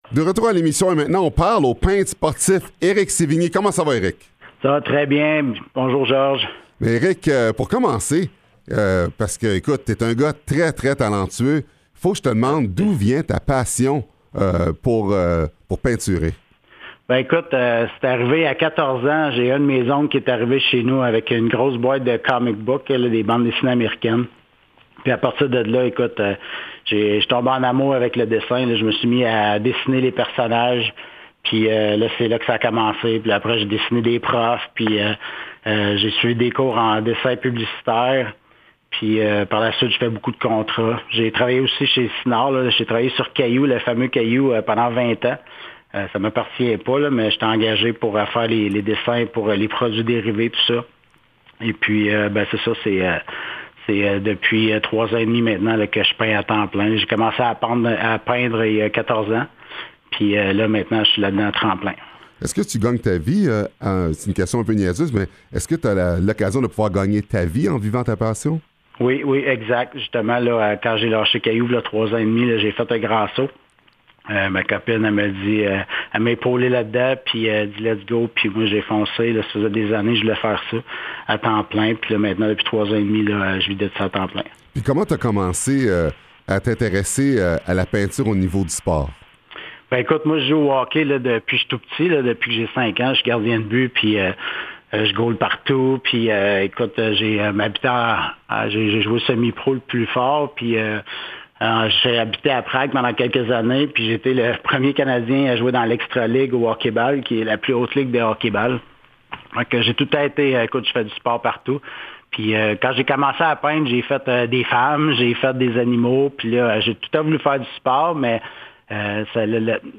Pour ré-écouter l'entrevue, suivez le lien ci-dessous (français seulement):